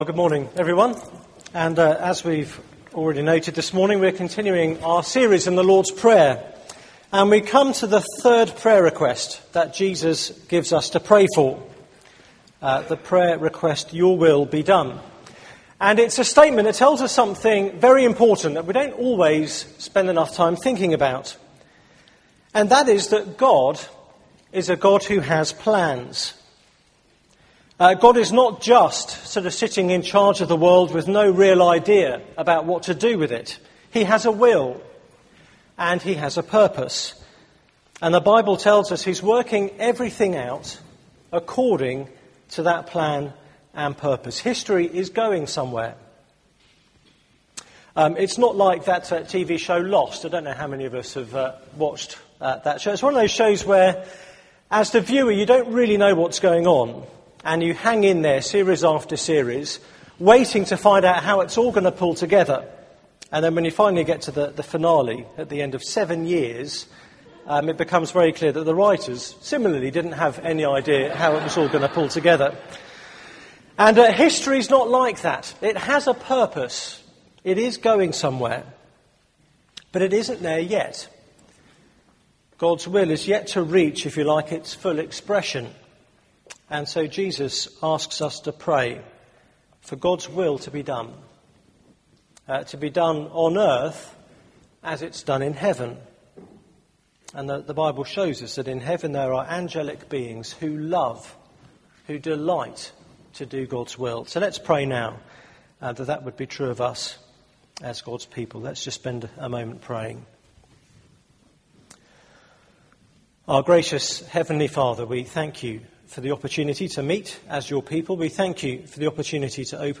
Media for 9:15am Service on Sun 10th Oct 2010 09:15 Speaker
Sermon Search the media library There are recordings here going back several years.